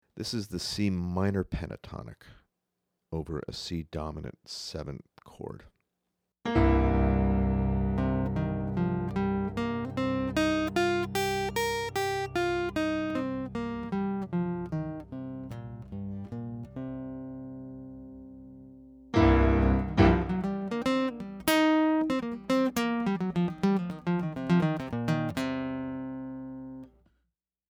14.Cmin.pent.overC7.mp3